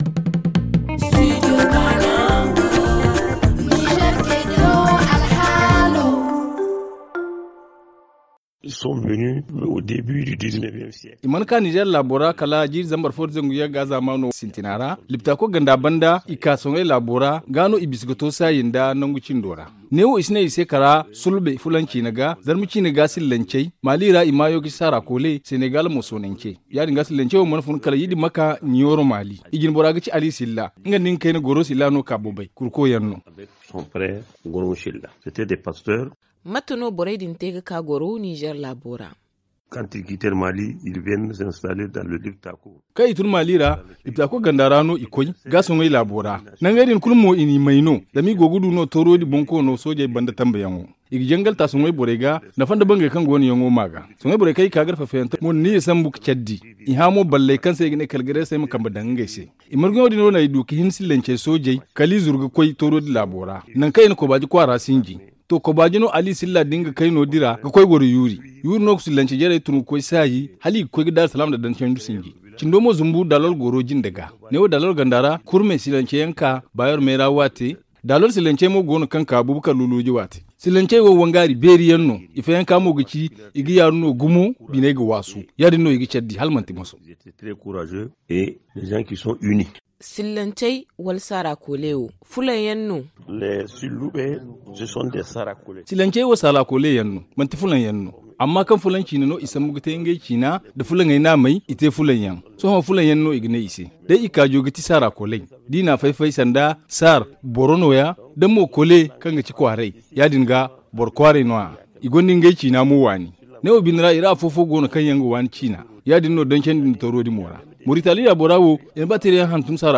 Le journal en zarma